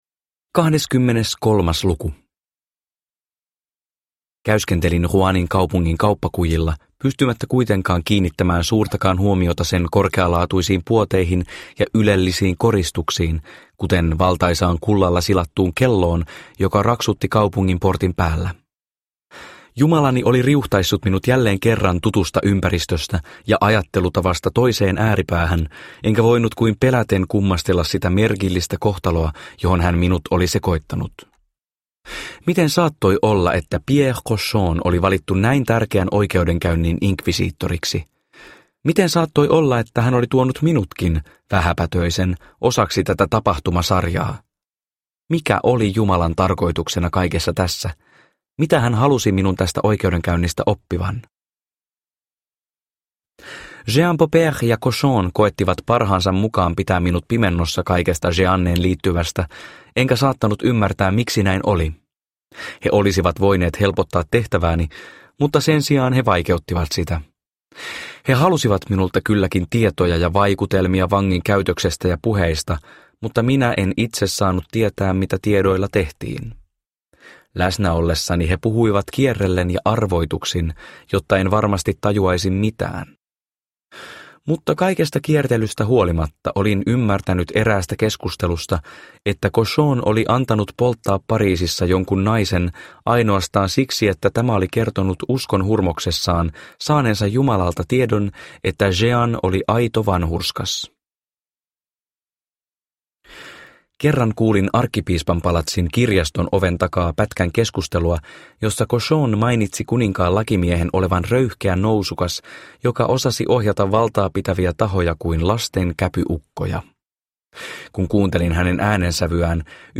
Kalmantanssi – Ljudbok – Laddas ner